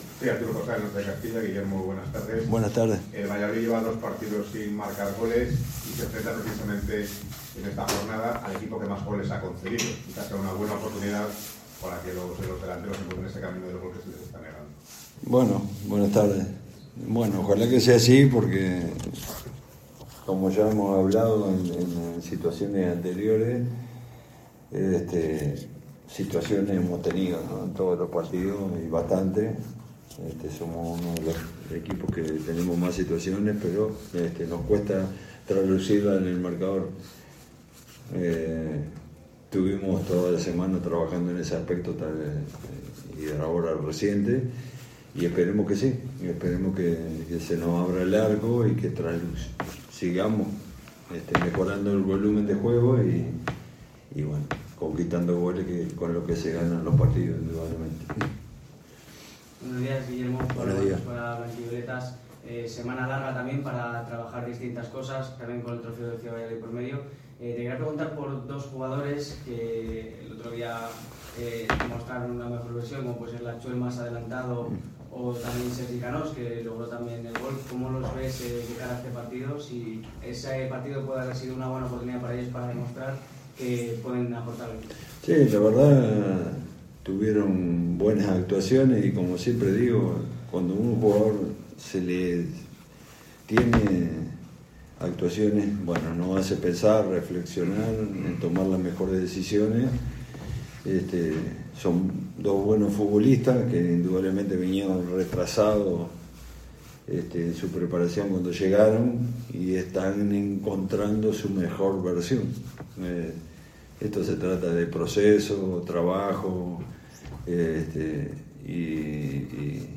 aquí la rueda de prensa completa